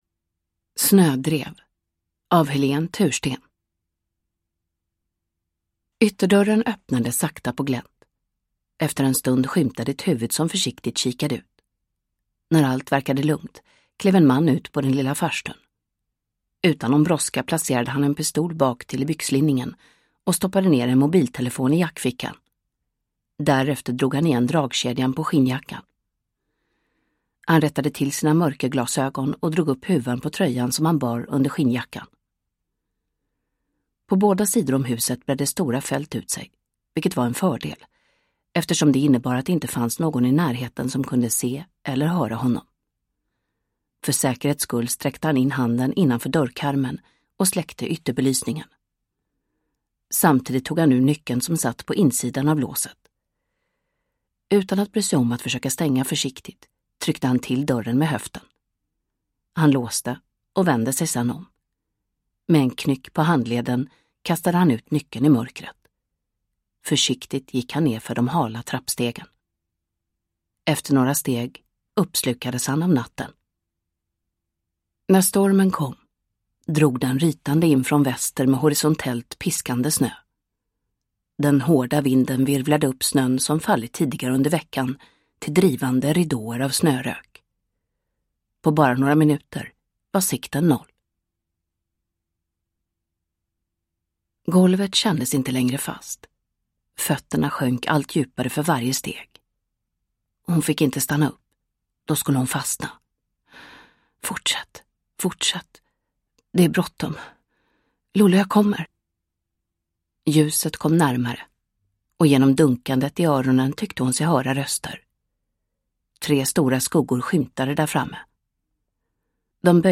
Snödrev – Ljudbok